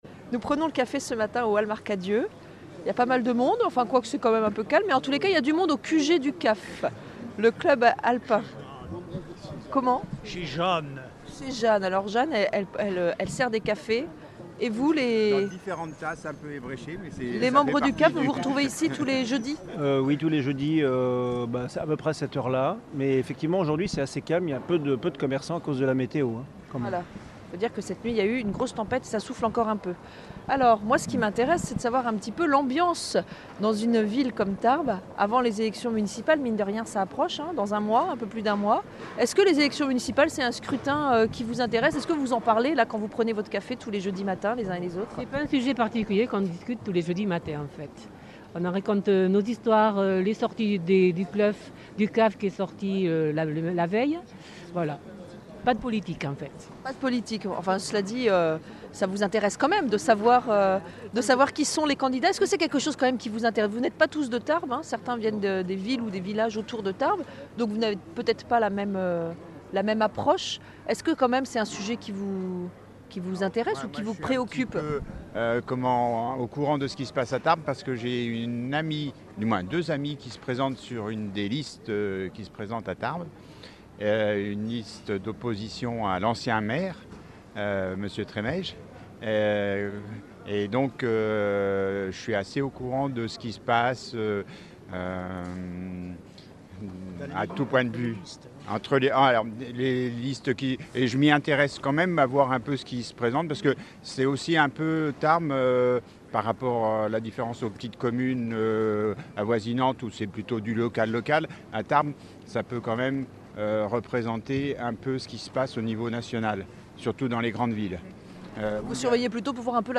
Avant les élections municpales, nous tendons le micro sur le marché de Tarbes.
Accueil \ Emissions \ Information \ Locale \ Interview et reportage \ Avant les élections municpales, nous tendons le micro sur le marché de Tarbes.